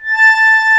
A5 ACCORDI-L.wav